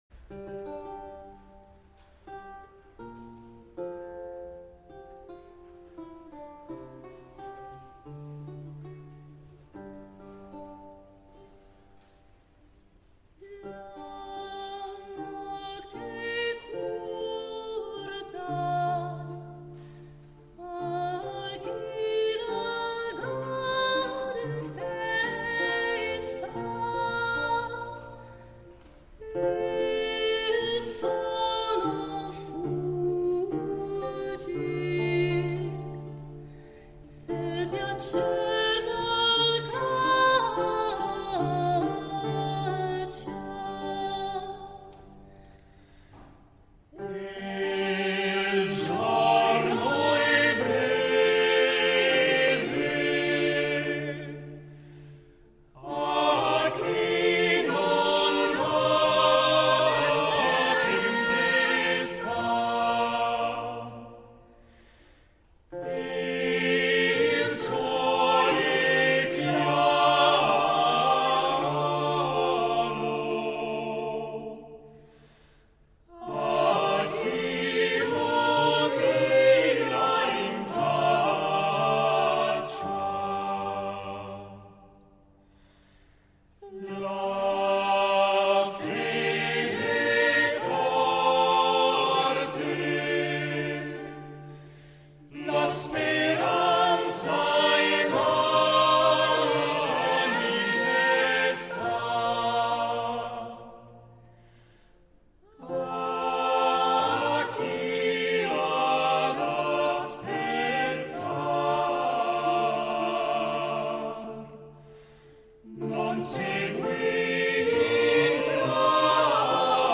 Perciò questo Orfeo è un’opera scenica, una forma di teatro musicale, con una particolarità: si giunge alle sorgenti del Recitativo e la poesia è indivisibile dalla musica.
I ruoli sono stati affidati a giovani cantanti accompagnati da strumentisti provenienti dalla Svizzera, dalla Francia, dall’Italia e dalla Germania che si sono riuniti per approfondire il repertorio italiano – e fiorentino più precisamente – della fine del XV secolo.